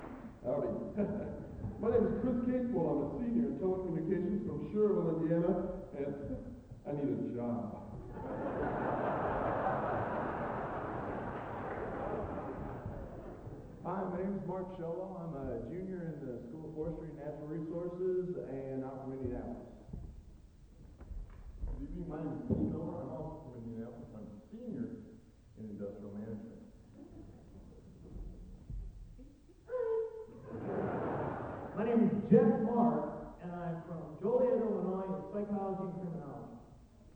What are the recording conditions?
Collection: South Bend 1990